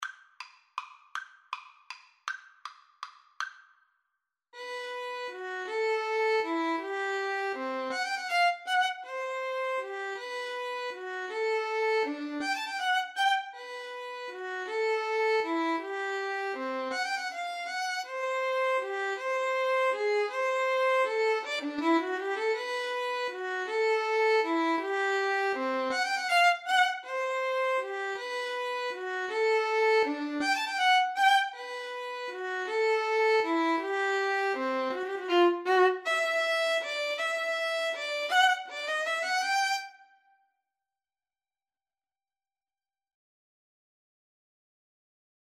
3/4 (View more 3/4 Music)
Allegro grazioso =160 (View more music marked Allegro)
Classical (View more Classical Violin-Viola Duet Music)